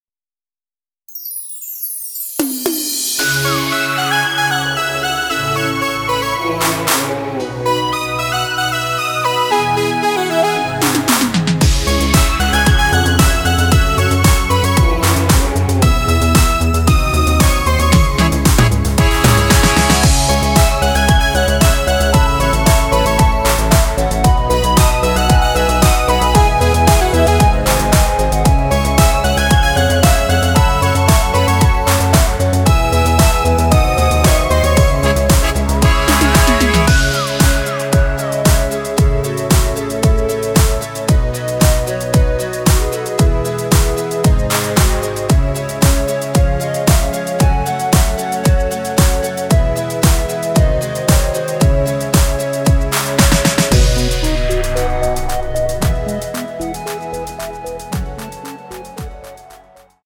원키에서(-1)내린 멜로디 포함된 MR입니다.
C#m
앞부분30초, 뒷부분30초씩 편집해서 올려 드리고 있습니다.
중간에 음이 끈어지고 다시 나오는 이유는